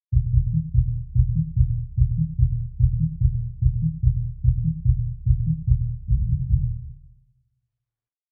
Звуки бытовые
Игра на барабанах за стеной или выше, низкие частоты гремят сквозь нее, особенно бас